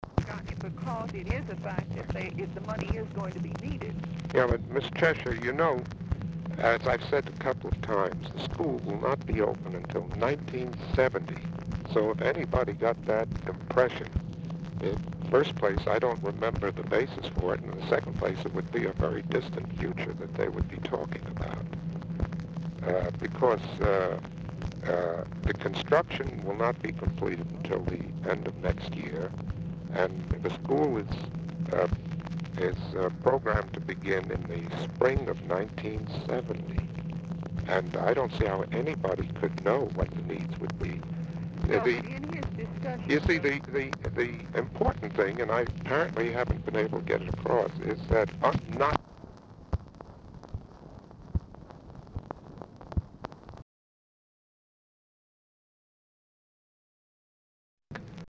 Telephone conversation # 13426, sound recording, ARTHUR KRIM and MAXINE CHESHIRE, 9/27/1968, time unknown | Discover LBJ
Format Dictation belt
Specific Item Type Telephone conversation